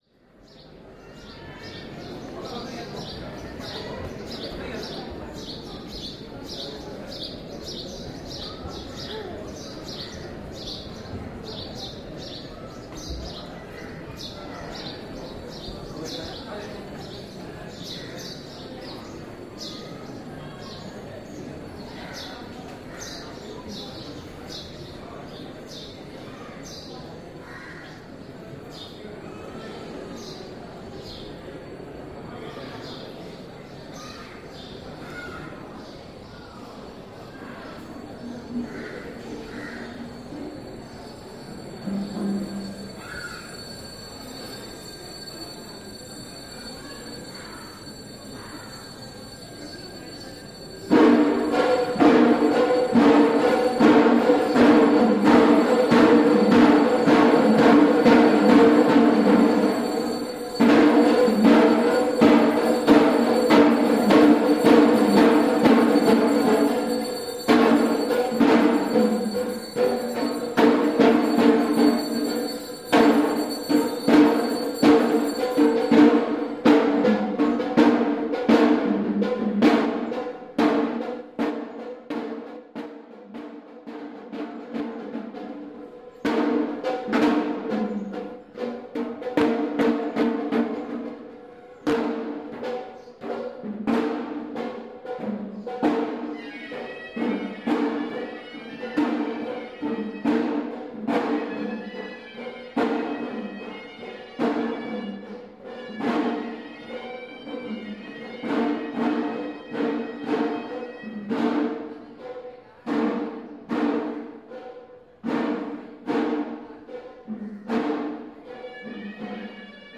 the Tooth Temple, musicians and ceremony.